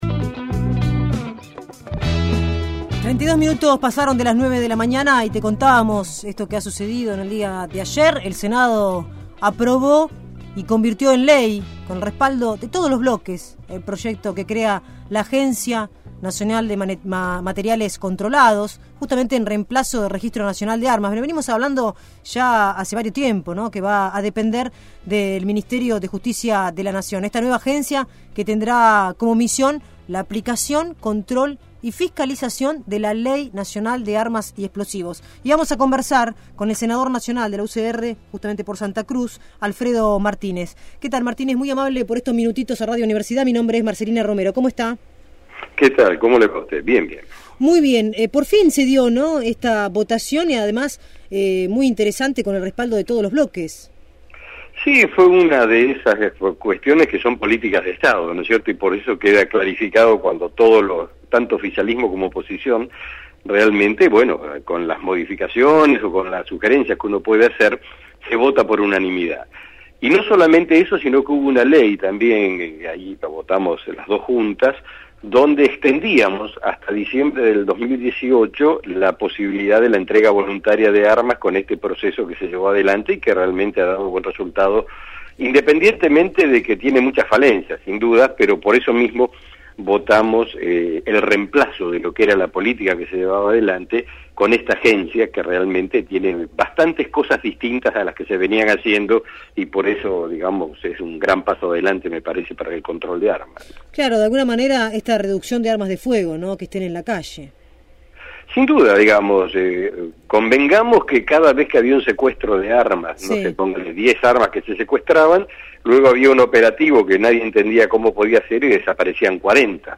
Alfredo Martínez, senador nacional por Santa Cruz (UCR), dialogó